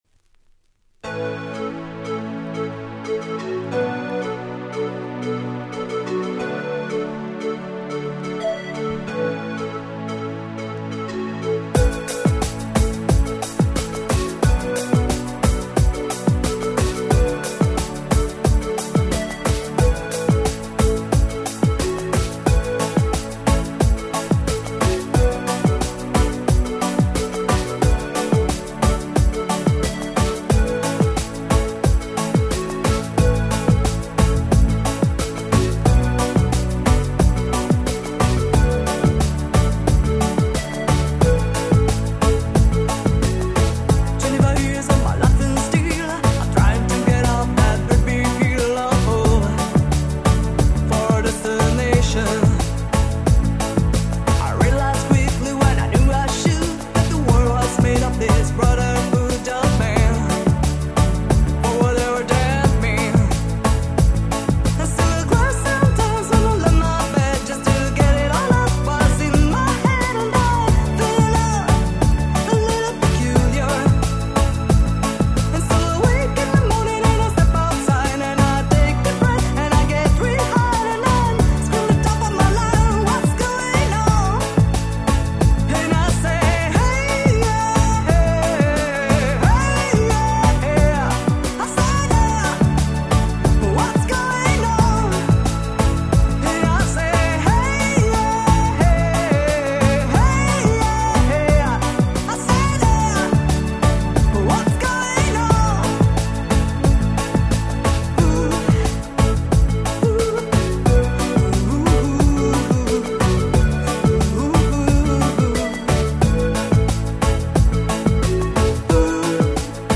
93' Nice Cover Ground Beat/Ace Beat !!